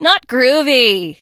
janet_hurt_vo_04.ogg